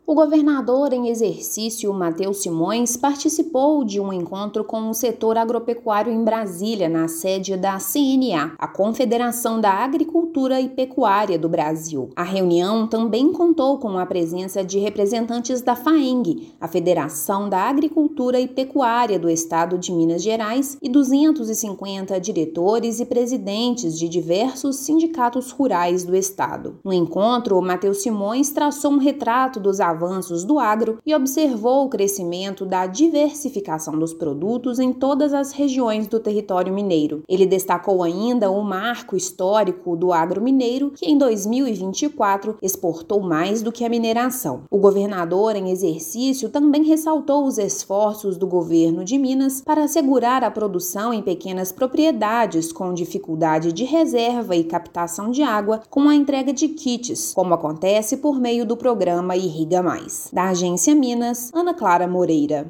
Governador em exercício apontou panorama do setor no estado na sede da Confederação da Agricultura e Pecuária do Brasil (CNA). Ouça matéria de rádio.